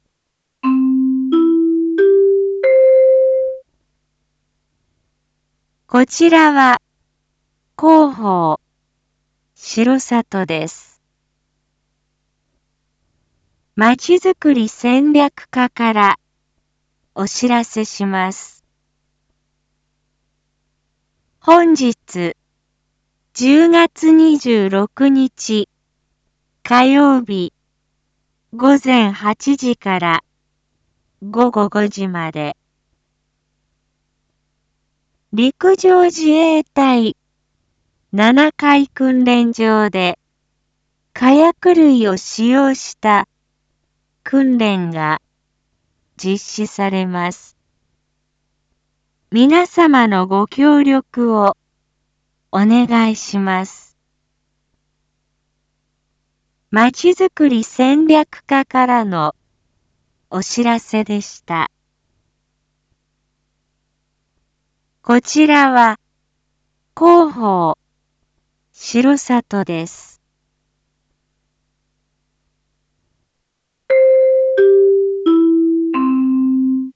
一般放送情報
Back Home 一般放送情報 音声放送 再生 一般放送情報 登録日時：2021-10-26 07:06:18 タイトル：R3.10.26 7時 インフォメーション：こちらは広報しろさとです。